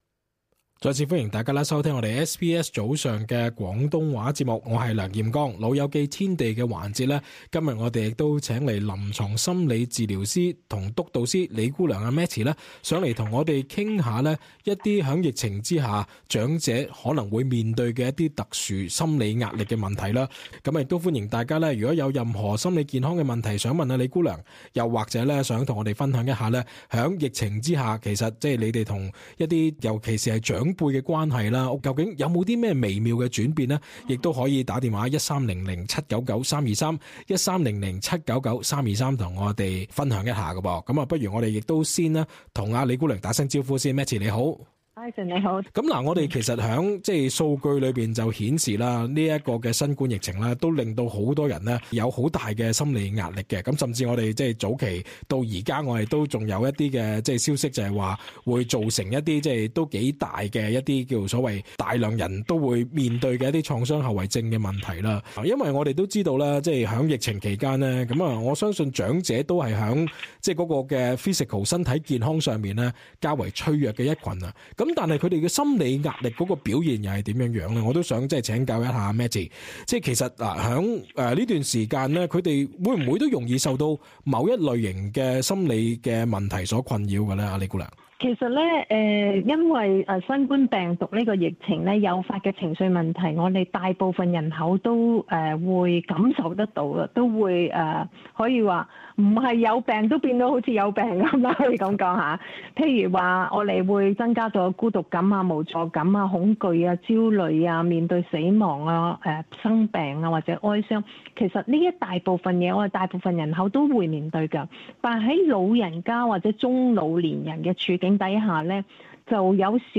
更多訪問內容，請收聽節目的足本訪問。